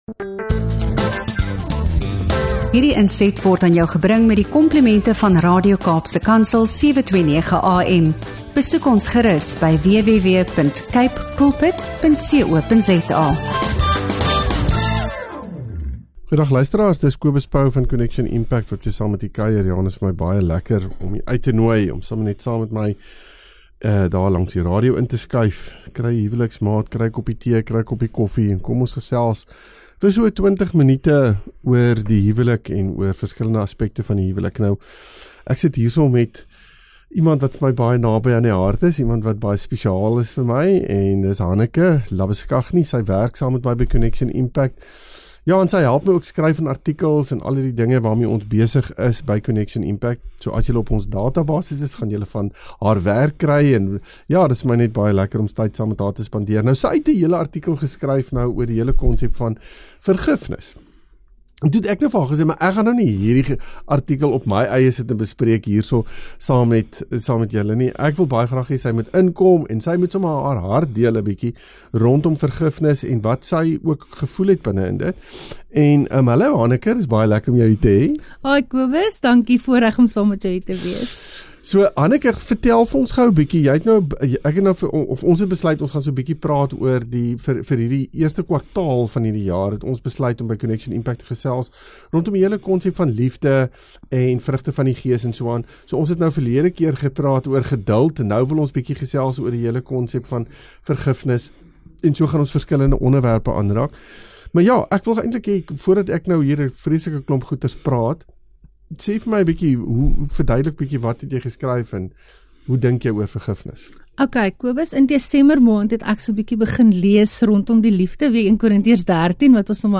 Maak ’n koppie koffie, skuif nader, en luister saam na ’n eerlike gesprek oor liefde, vergifnis en die krag van keuses.